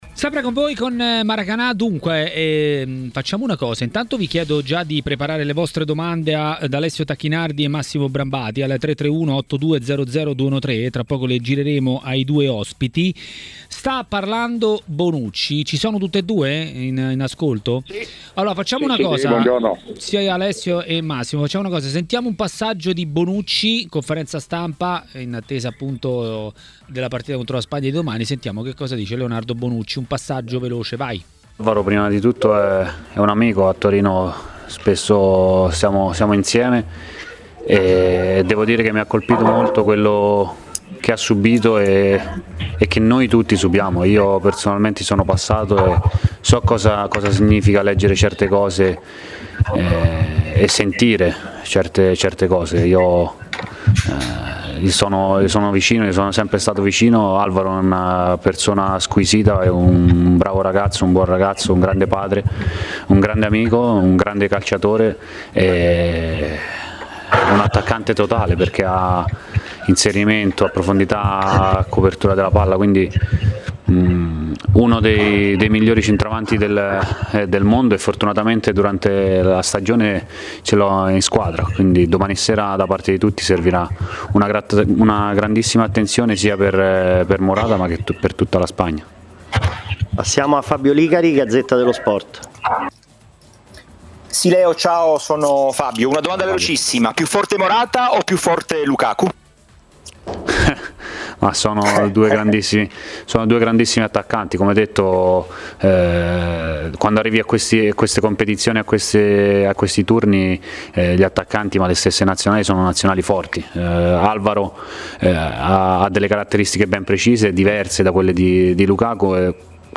L'ex calciatore e tecnico Alessio Tacchinardi a TMW Radio, durante Maracanà, ha parlato di Juventus ma non solo